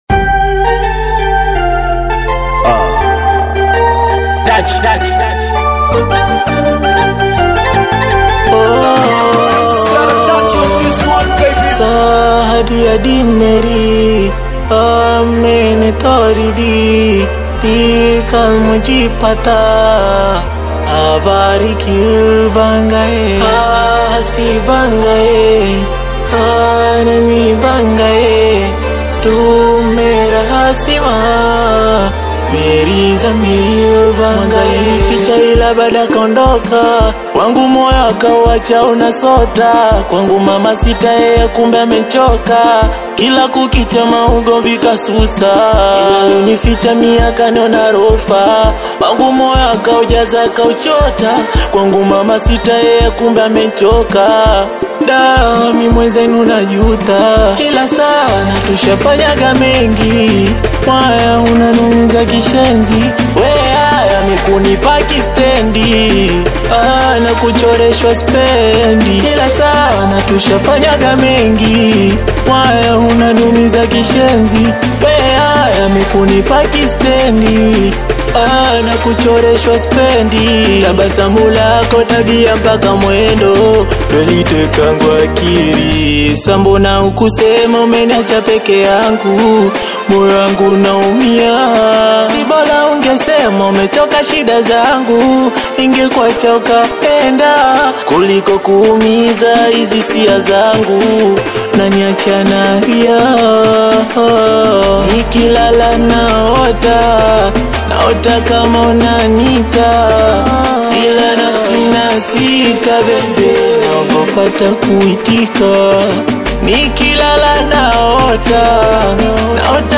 Singeli AUDIO